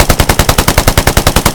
smg-mid-1.ogg